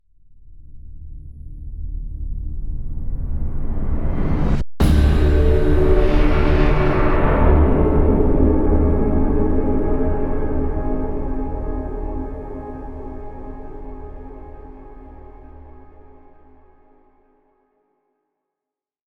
Impact - Star Trek spaceship engine start
engine impact sound-design space spaceship start star-trek univers sound effect free sound royalty free Memes